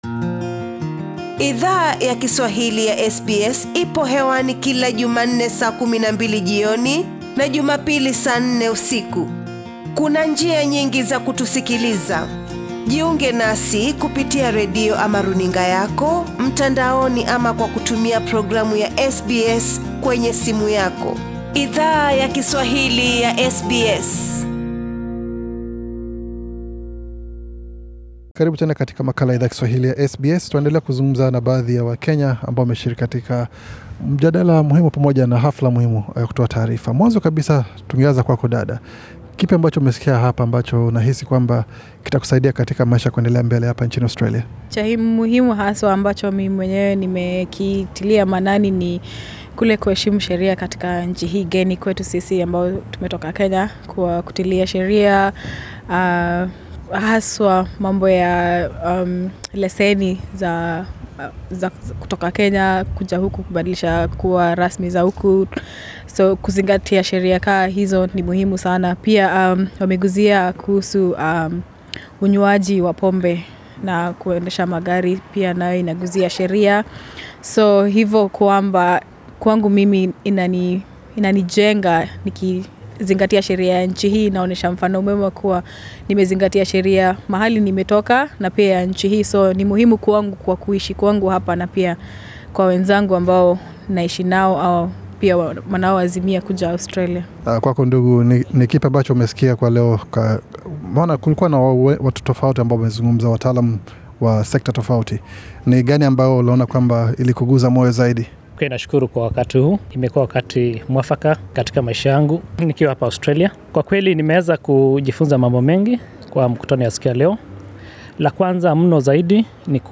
Idhaa ya Kiswahili ya SBS ilihudhuria mkutano maalum katika kitongoji cha Auburn, ambako shirika la KISWA lili alika wataalam tofauti, kuzungumza na wanachama wao kuhusu mada tofauti zinazo wahusu wanachama wao.